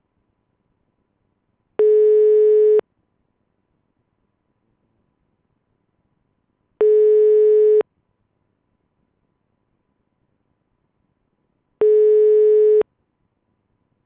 outgoingcall.wav